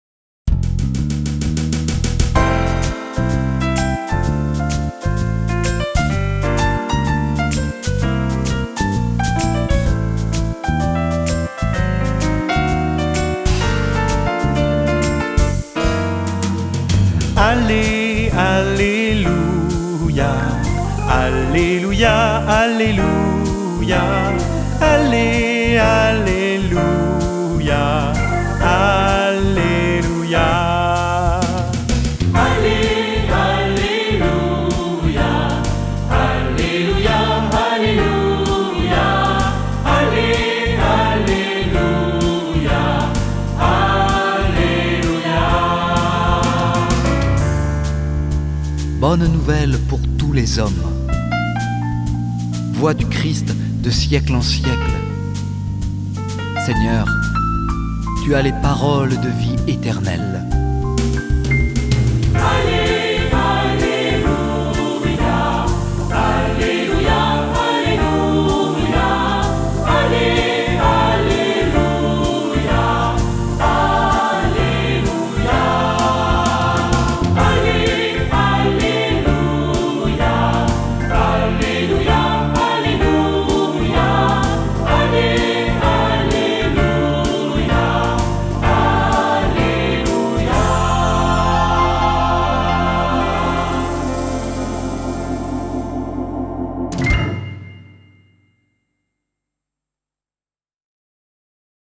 Célébration durant la journée communautaire à Saint-Gabriel.
messe-de-la-saint-jean-alleluia.mp3